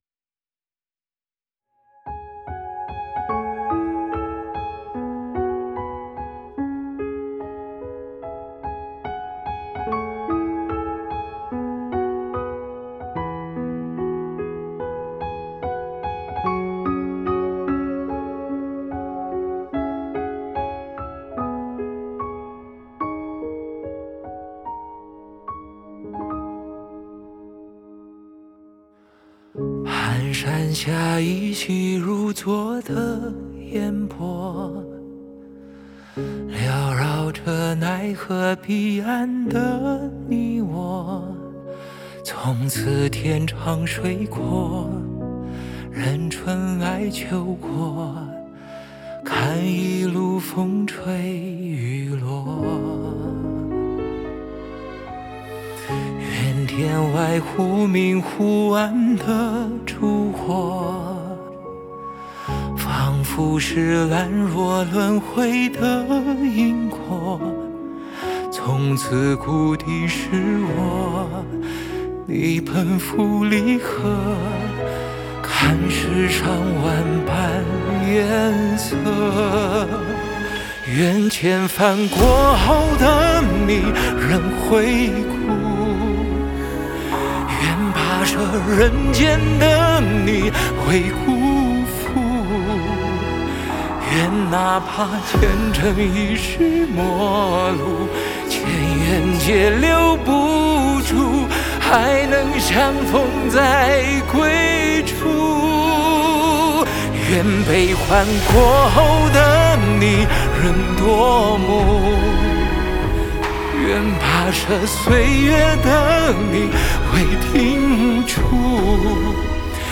Ps：在线试听为压缩音质节选，体验无损音质请下载完整版
弦乐